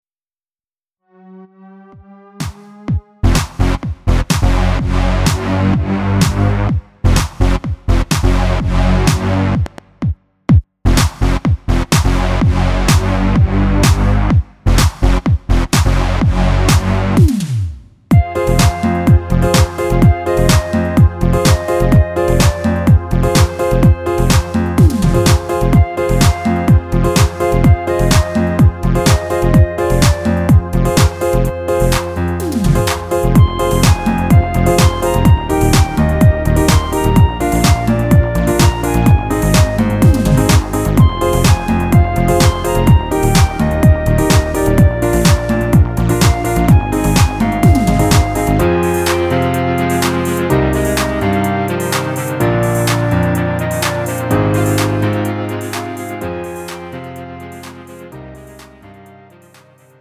음정 원키
장르 pop 구분 Lite MR